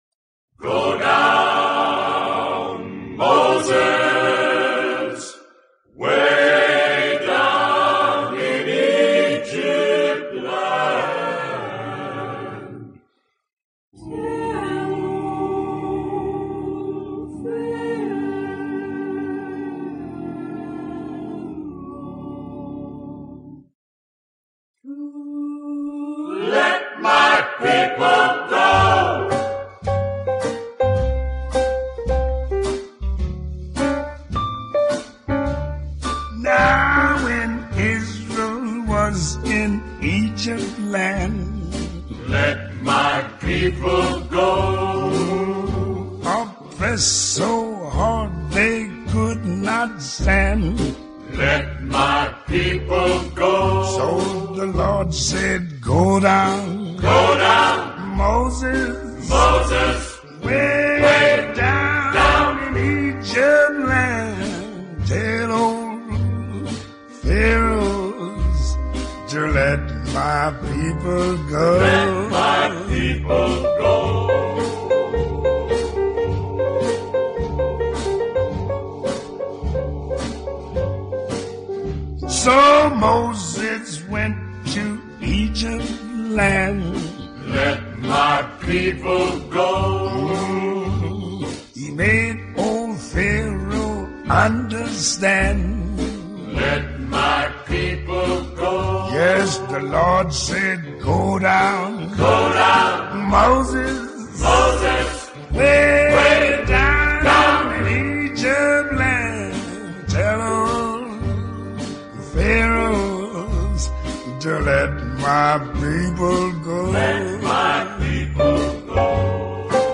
Жанр: Jazz